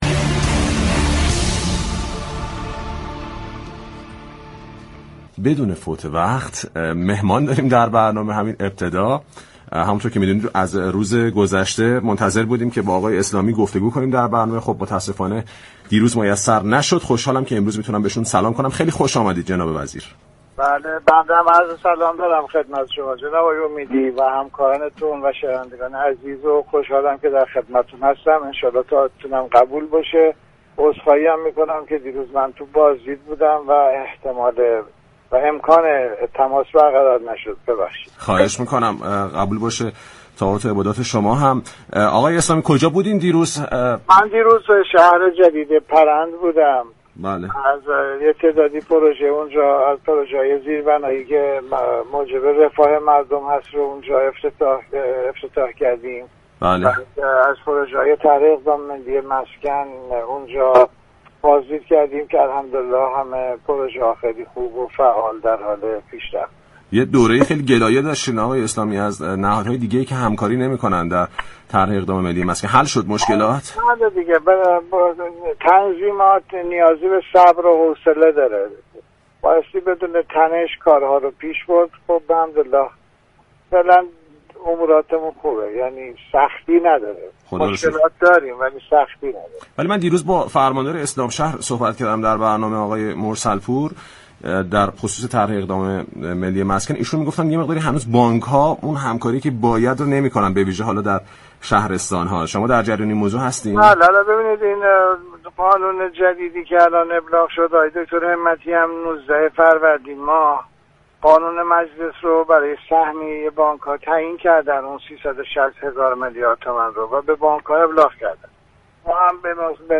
به گزارش پایگاه اطلاع رسانی رادیو تهران، محمد اسلامی وزیر مسكن، راه و شهرسازی در گفتگوی خود با برنامه بازار تهران درباره بازدید از شهر پرند و بهره برداری از طرح های عمرانی و زیربنایی این شهر گفت: از پروژه های طرح اقدام ملی مسكن در این شهر هم بازدید كردیم ، تمامی پروژه ها فعال بوده و در مرحله خوبی قرار دارند اگرچه تنظیمات نیاز به صبر و حوصله دارد و پروژه ها باید بدون تنش انجام شود.